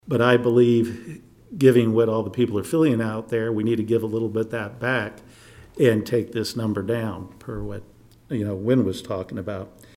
Commissioner John Matta says utilizing some of the federal Coronavirus relief funds currently in the city’s cash reserves could help give taxpayers at least some relief.